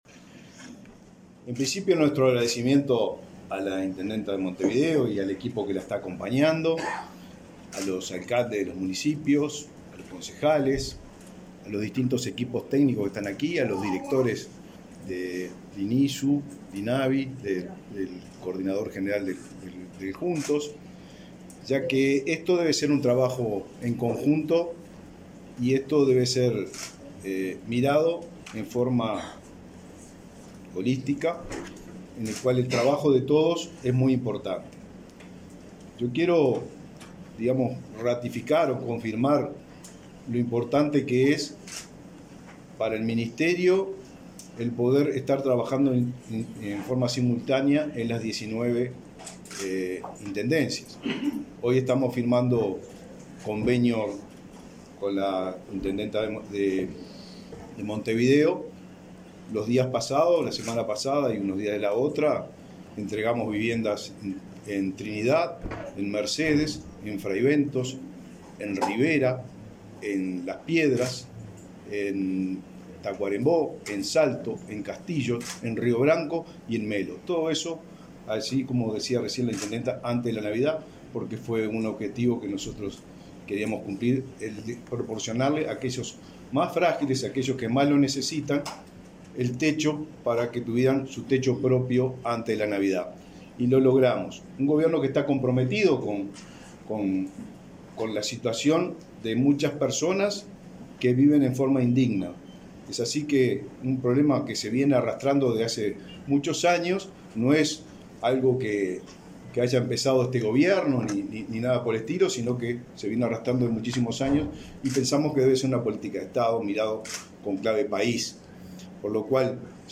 Palabras del ministro de Vivienda, Raúl Lozano
Representantes del Ministerio de Vivienda y Ordenamiento Territorial (MVOT), el programa Juntos y la Intendencia de Montevideo firmaron un convenio para la ejecución de un proyecto sociohabitacional que permitirá el realojo de hasta 46 familias de la zona de Felipe Cardozo, en Montevideo. El titular del MVOT, Raúl Lozano, destacó la importancia del acuerdo.